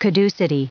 added pronounciation and merriam webster audio
761_caducity.ogg